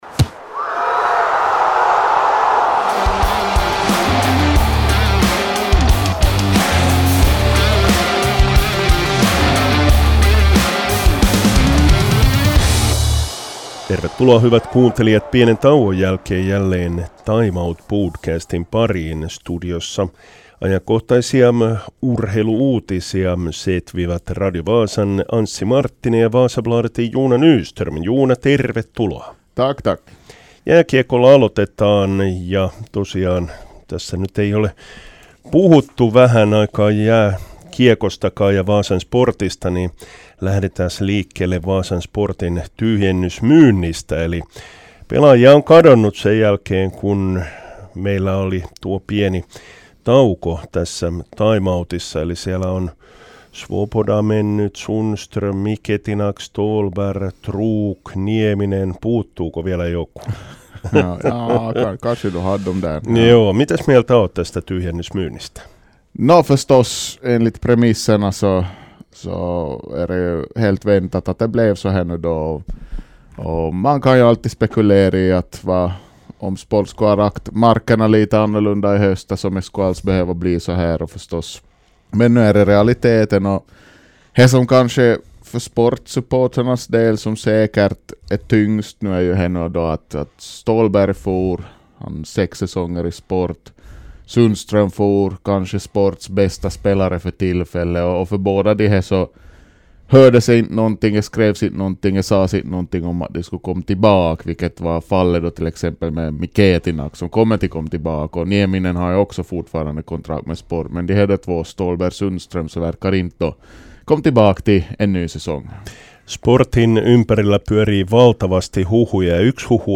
I studion